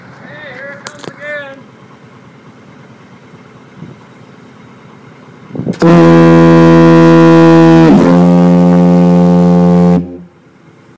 20세기 초부터 로버트 호프-존스(Robert Hope-Jones)가 오르간 스톱으로 처음 발명하고, 토론토의 존 노시(John Northey)가 안개 신호용으로 개량한 다이아폰이 전 세계의 새로운 안개 경적 설치에 표준 장치로 자리 잡았다.[8] 다이아폰은 압축 공기를 사용하여 매우 강력한 저주파음을 방출할 수 있었다.
캘리포니아 리치몬드의 이스트 브라더 아일랜드 등대에서 녹음된 다이아폰 소리